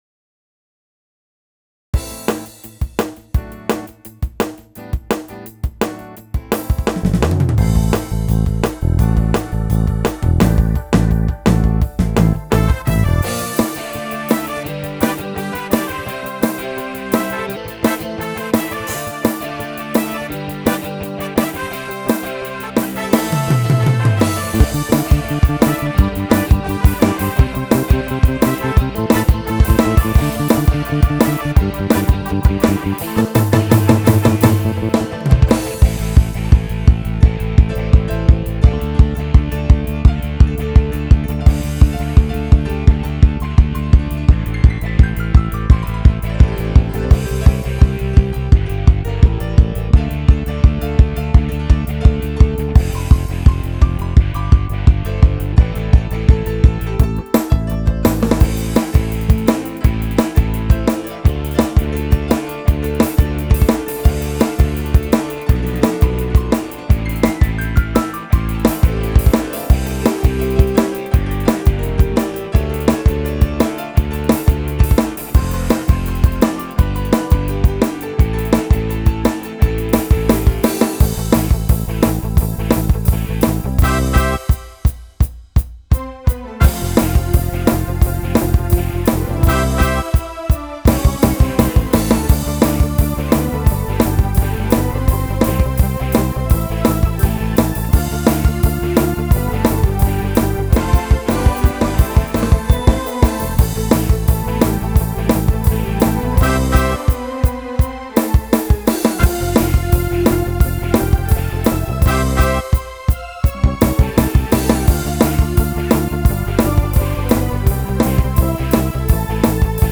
מחרוזת דאנס- רוק.wav